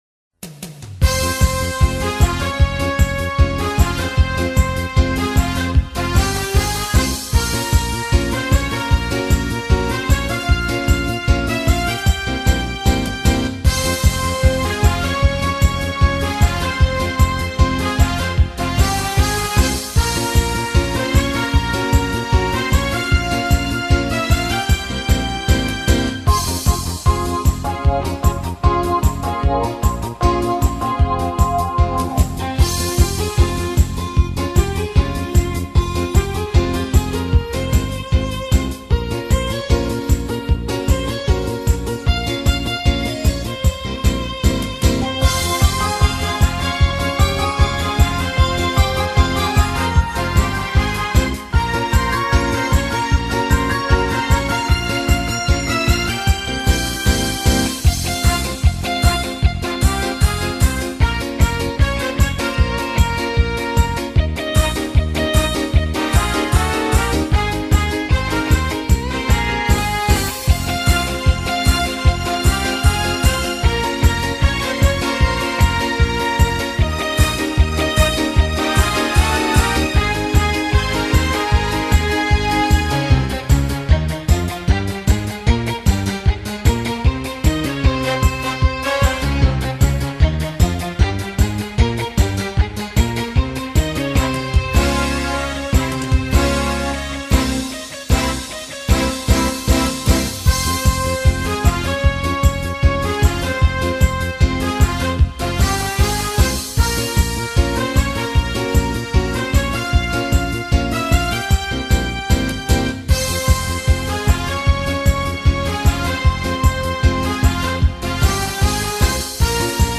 ¡Bailemos en familia! Mejor que la zumba y el reguetón, la música electrónica del gym y los bailes de salón: para ahuyentar los males lo más eficaz es sacudir el cuerpo rítmicamente con la alegría que contagian las melodías y cantos jasídicos.